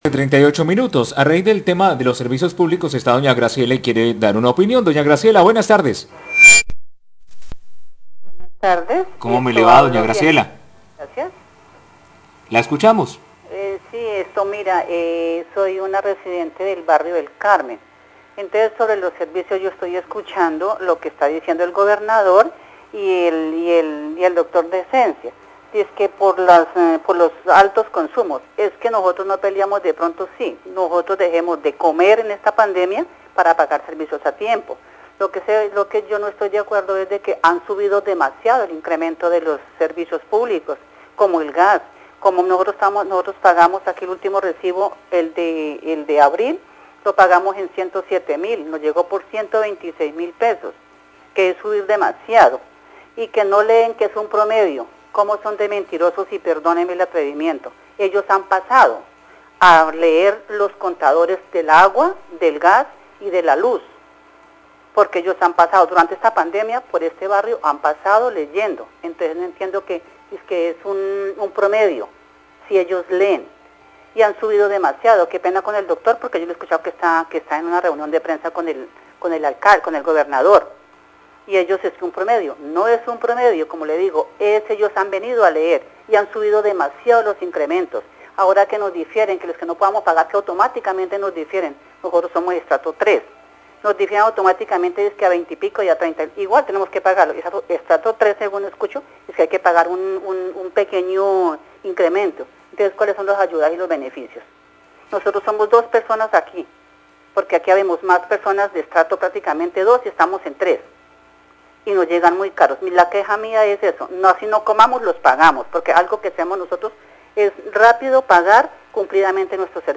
Radio
Tras escuchar la rueda de prensa, usuarios han llamado a la emisora Ecos del Combeima a contarle a los oyentes sobre lo que ellos consideran es mentira pues ellos aseguran que el aumento en el precio de la factura es exagerado y dicen que diferir las facturas en realidad no ayuda a ningún usuario porque el incremento continúa.